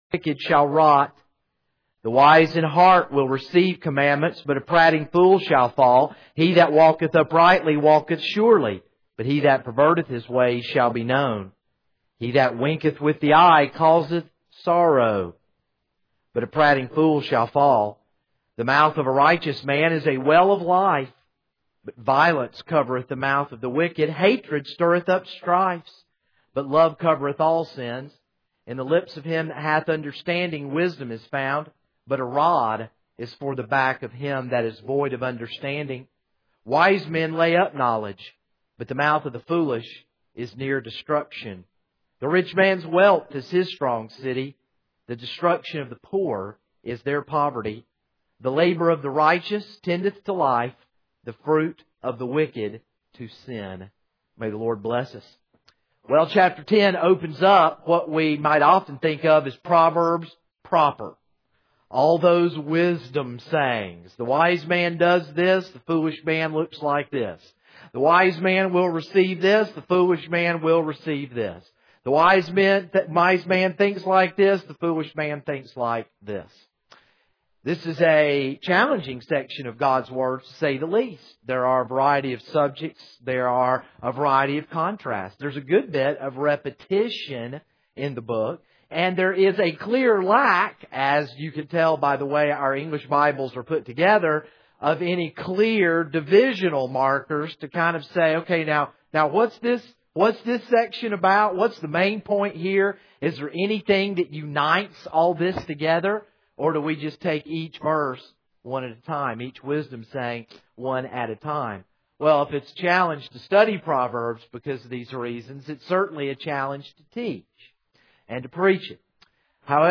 This is a sermon on Proverbs 10:1-16.